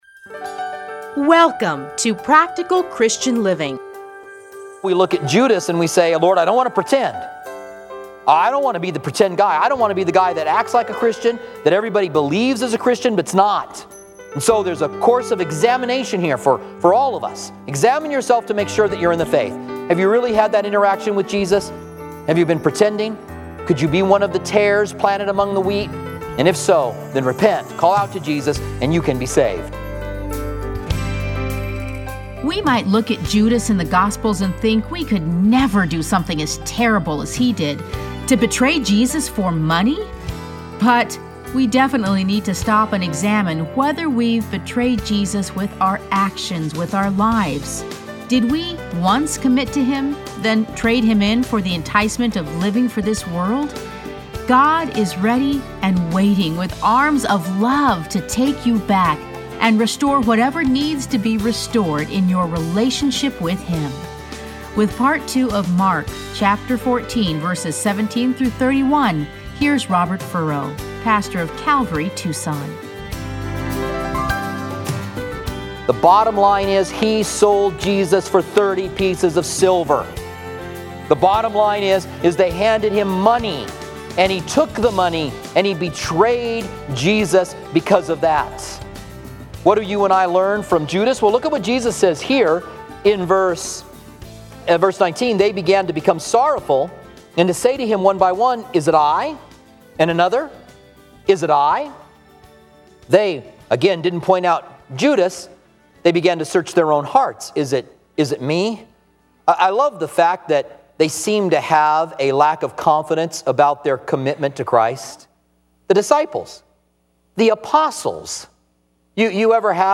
Listen to a teaching from Mark 14:17-31.